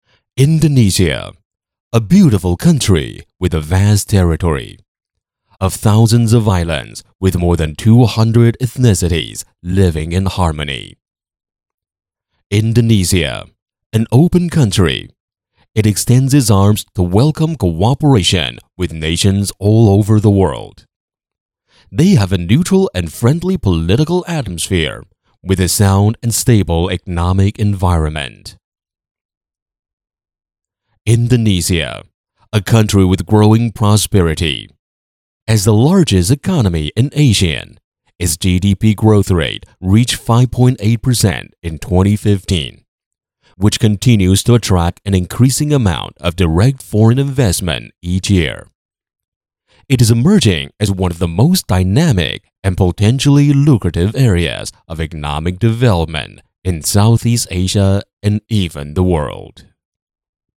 08男--科技现代] 华为宣传片-飞乐传媒官网